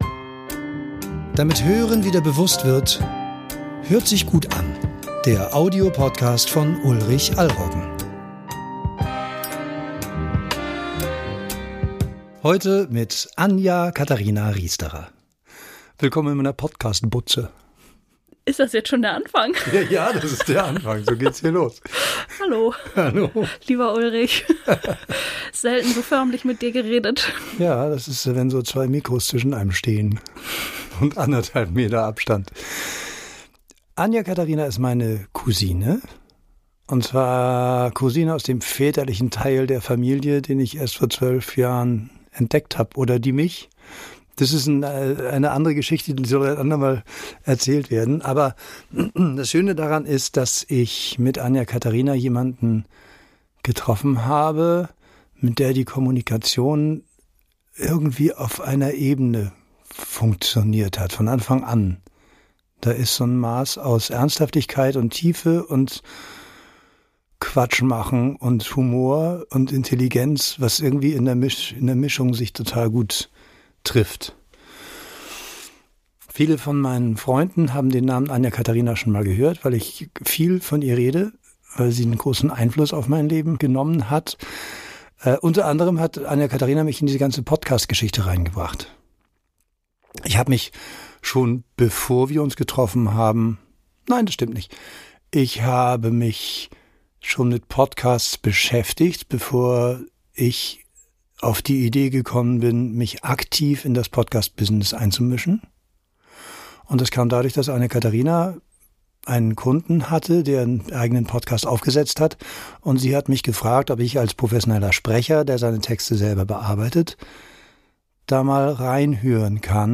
Daher ihr Angebot, mich zu meinen Motiven hinter diesem Podcast zu interviewen. Es stellt sich heraus, dass Sound für mich ein Beziehungsthema ist, wir sprechen über meinen ersten Berufswunsch nach dem Abitur und wie ich konkret dazu beitragen habe, den Podcast eines gemeinsamen Kunden soundmäßig aufzuwerten.